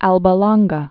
(ălbə lônggə, lōng-)